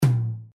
tom2.wav